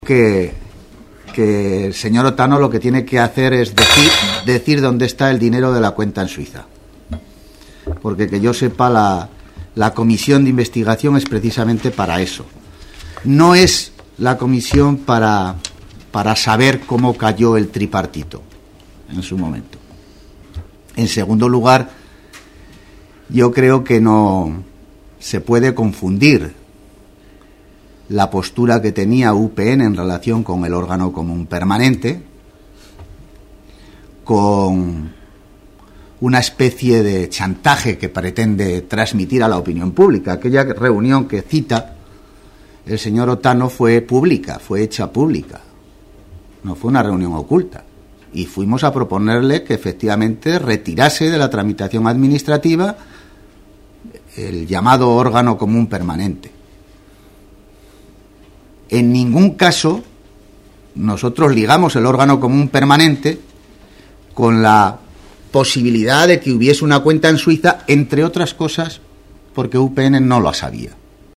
El Presidente Sanz, a preguntas de los periodistas sobre esta cuestión, ha afirmado esta mañana: "Lo que tiene que hacer el señor Otano es decir a la comisión parlamentaria donde está el dinero de una cuenta de la que él fue titular, y un titular es el que mete y saca el dinero de la cuenta y, en su caso, otorga poderes para hacerlo, y si él personalmente no manejó esos fondos, debe decir a quién dio los poderes para hacerlo".
Declaraciones del Presidente Sanz.